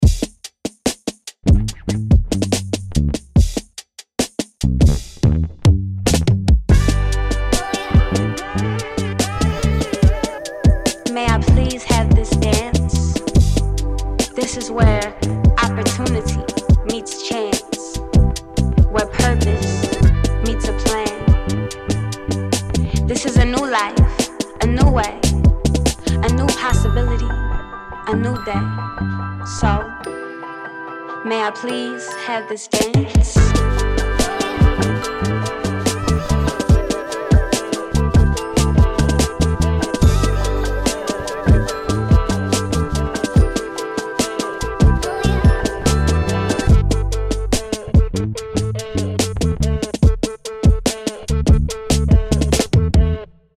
どんなトラックやミックスも、よりハードにヒットするウルトラデッド・ヴィンテージ・ドラムが魂を吹き込む